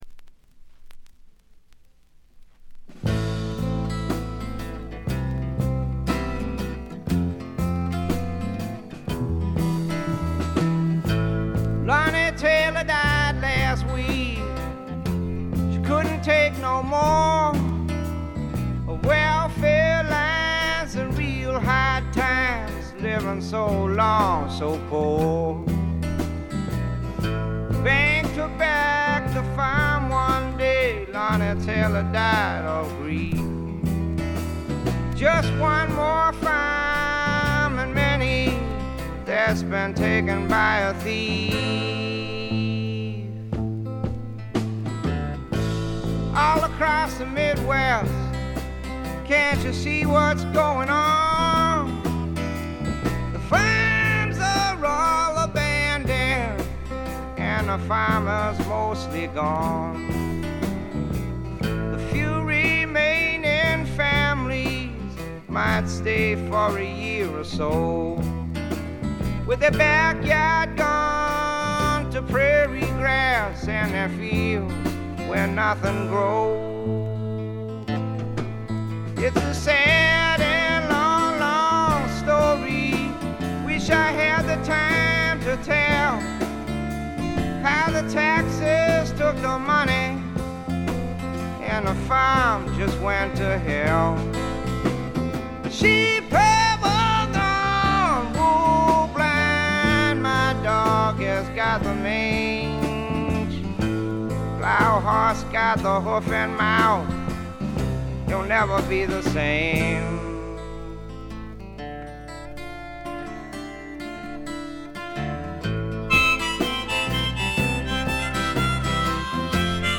ほとんどノイズ感無し。
試聴曲は現品からの取り込み音源です。
Vocals, Guitar, Harmonica